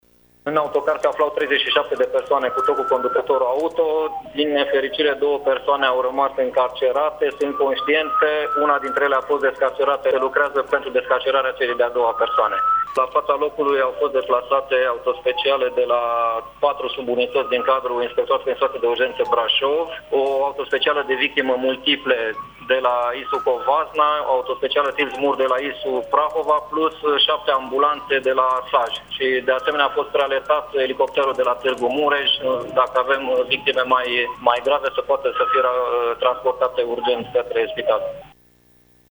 Deasemenea, elicopterul SMURD Tîrgu Mureș este pregătit să intervină, în cazul în care sunt persoane în stare gravă, a precizat șeful ISU Brașov, Lucian Marciu, într-o declarație televizată: